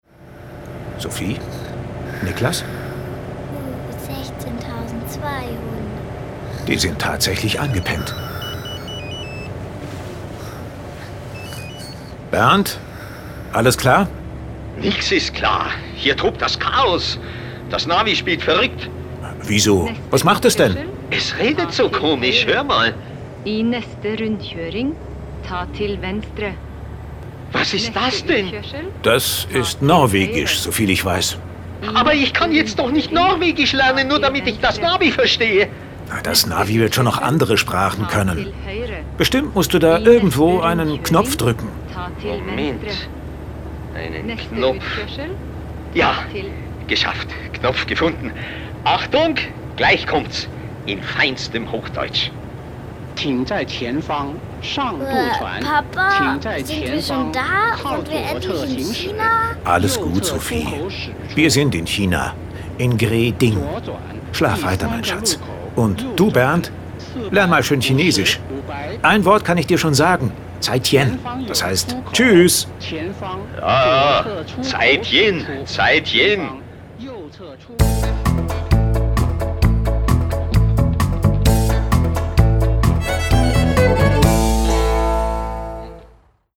Lustige Reime und Themensong